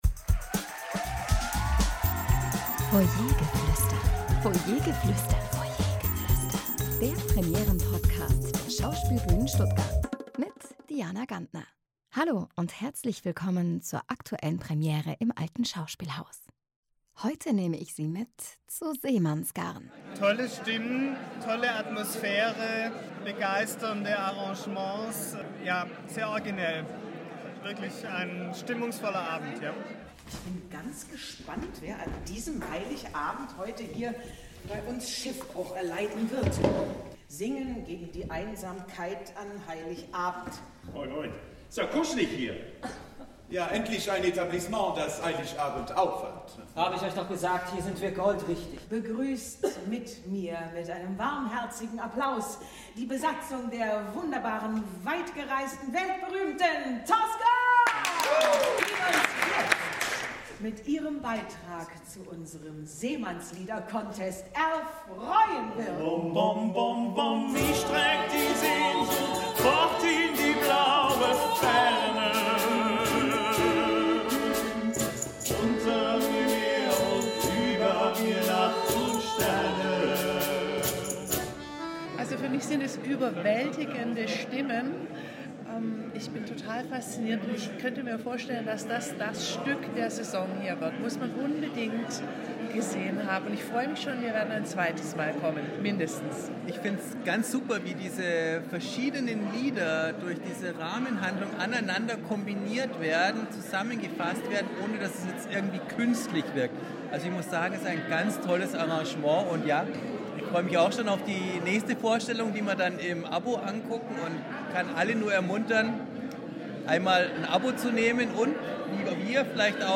Zuschauerstimmen zur Premiere von “Seemannsgarn”